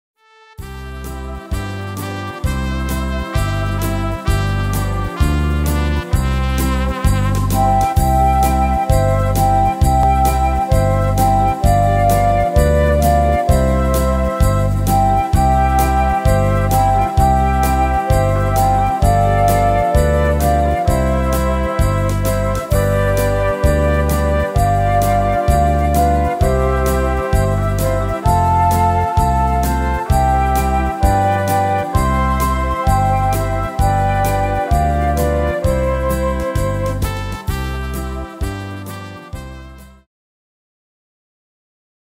Tempo: 130 / Tonart: C-Dur